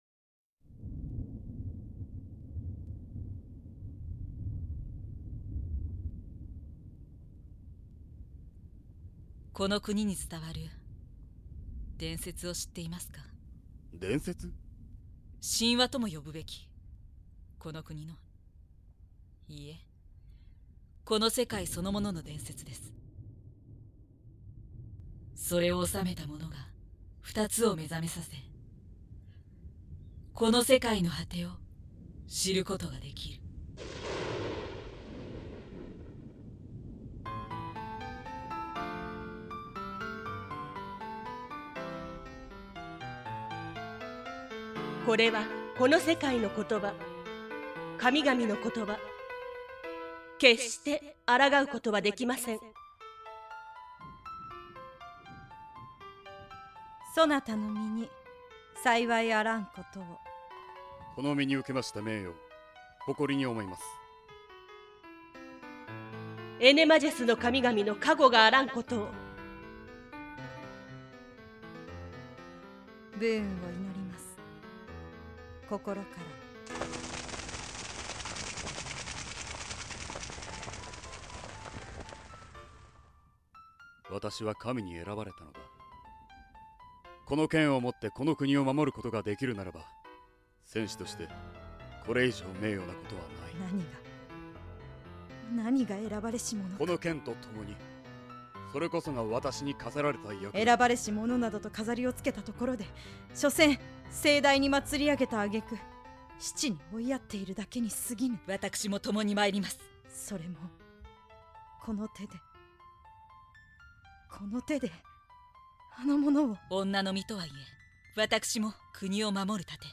◆ソウルディア◆ （自分で考える一人二役コンセプト）
マキ メンバーのリーダー的存在の女性。
ミヤ 浮世離れしたお姉さん。
（6/7※一部BGMにノイズが載ってしまっていたため、差し替えて再アップしました）